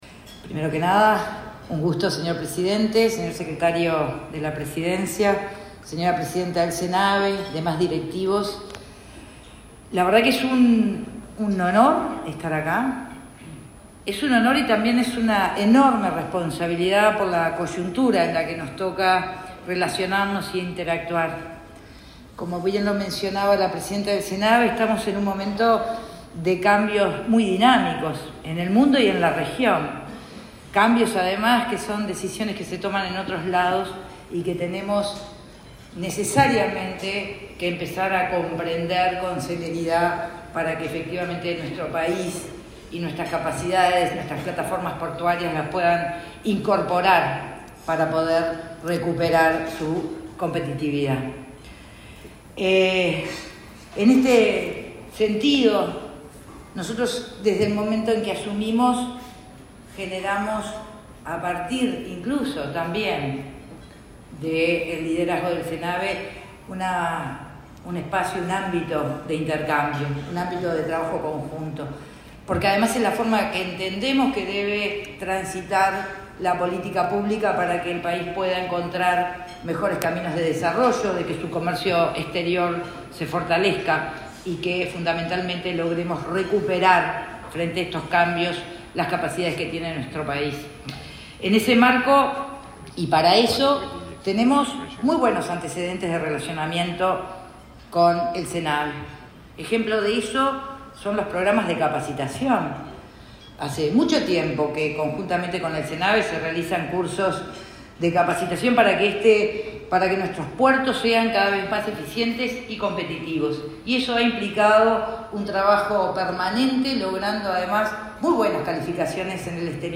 La ministra de Transporte, Lucía Etcheverry, expuso durante la ceremonia por el 109.° aniversario del Centro de Navegación.